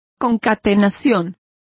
Complete with pronunciation of the translation of concatenations.